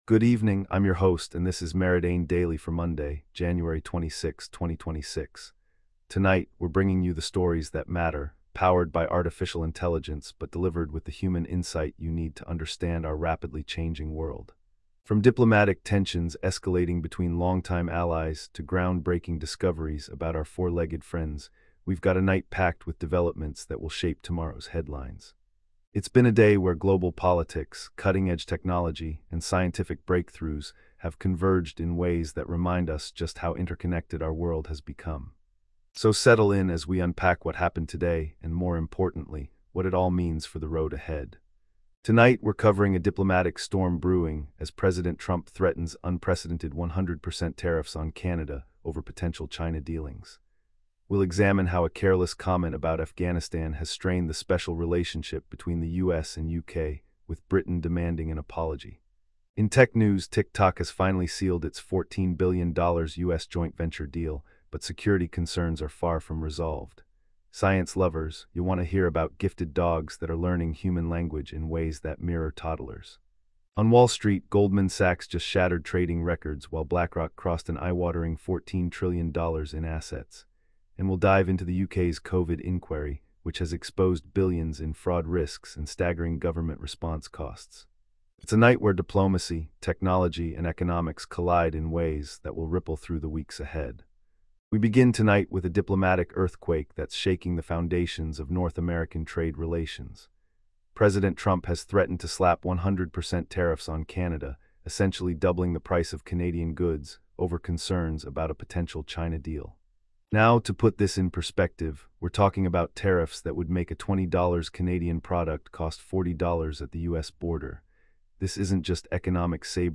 Your nightly AI-powered news briefing for Jan 26, 2026